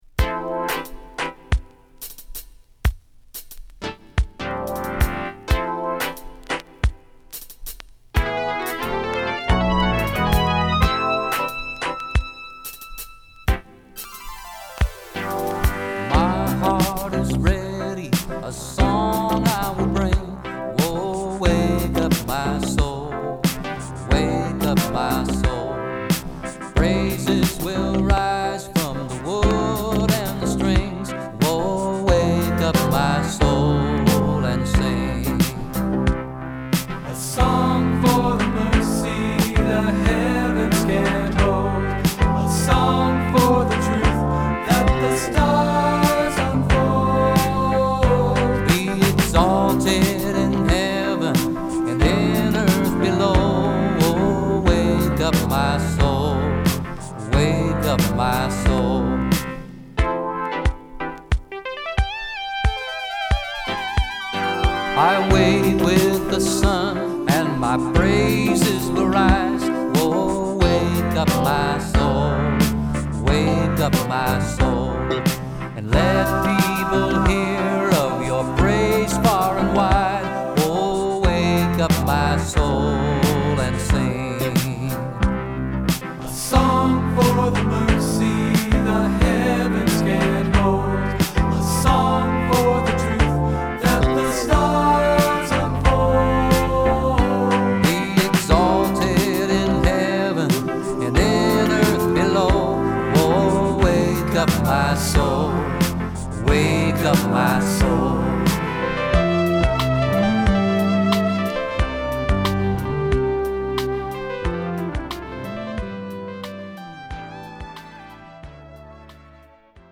7inch
男性のリードシンガーが、靭やかなシンセサウンドのメロウトラックに乗せて歌うアーバンソウル風味の1曲！...